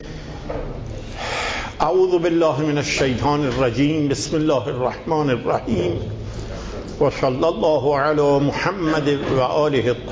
صوت و تقریر درس